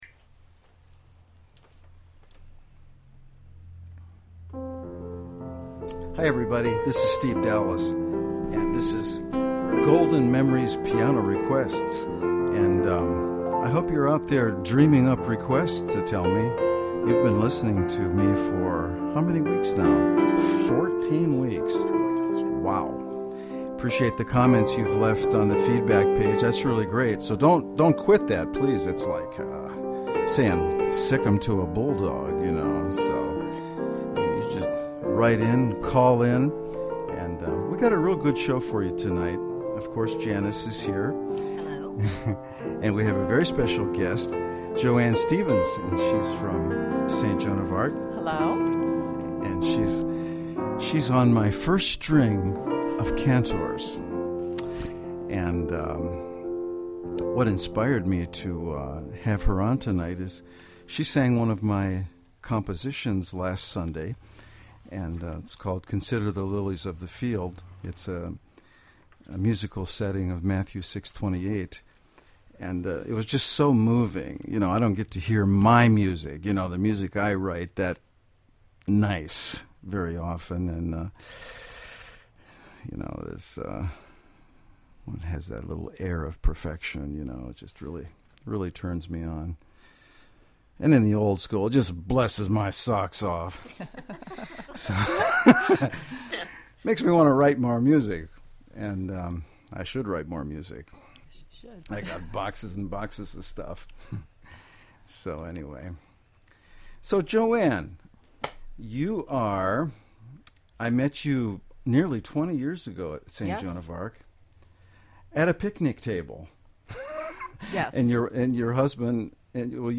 Golden oldies played to perfection!
And certainly give me a call with your song request (see listing of available numbers) and we’ll see if I can play it on the spot!